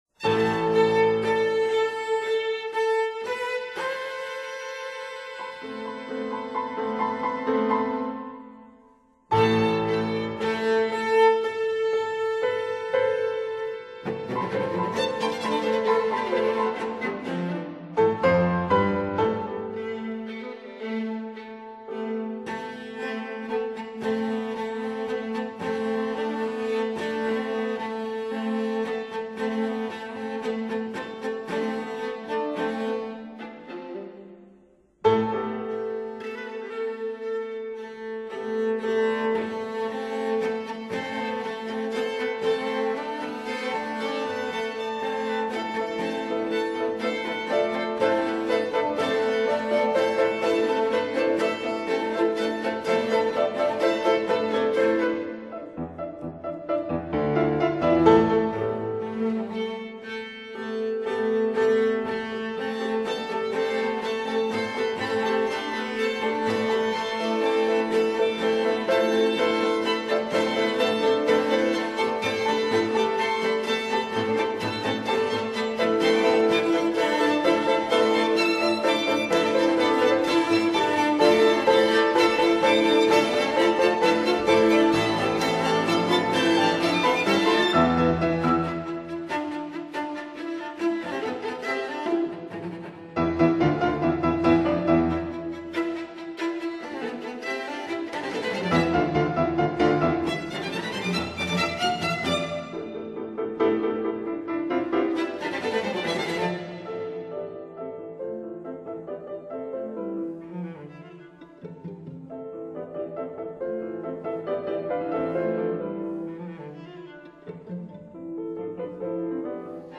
for violin, cello & piano